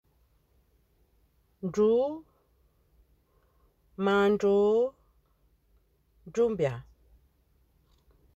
Accueil > Prononciation > dr > dr